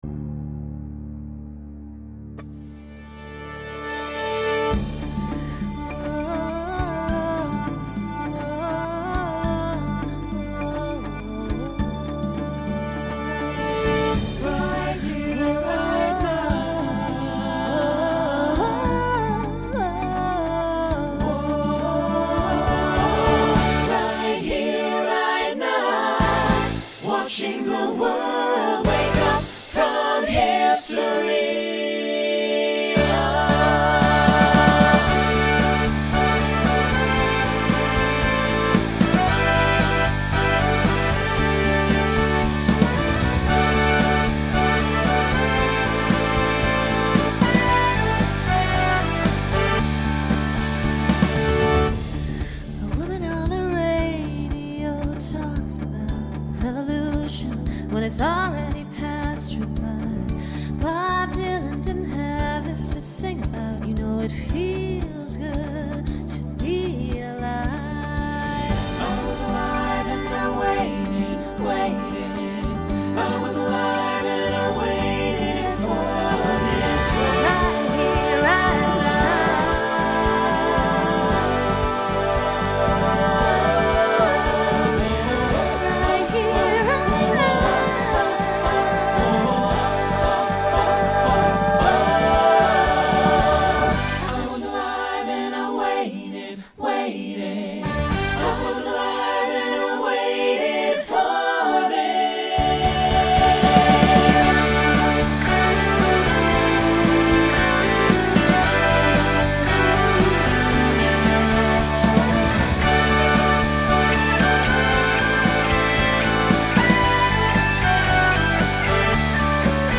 Pop/Dance
Mid-tempo , Opener Voicing SATB